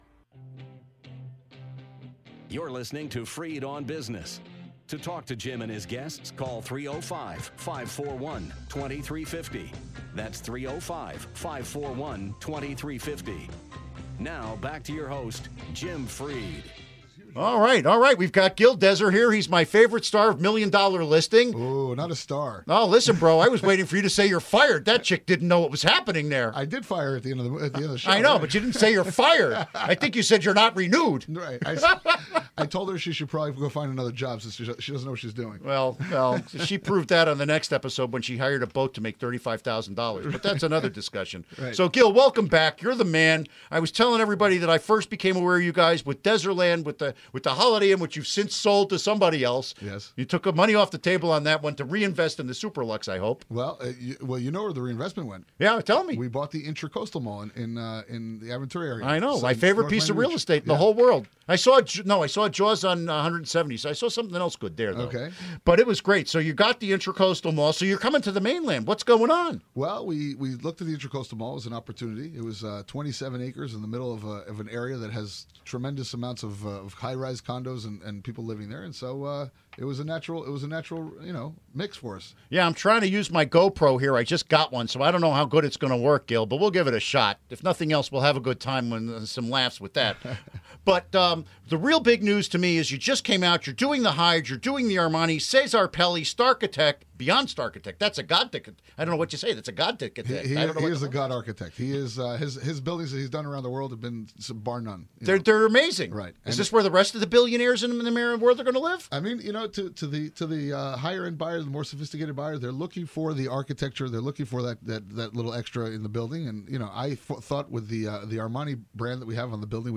Interview Segment Episode 275: 07-31-14 (To download, right-click this link and select “Save Link As”.)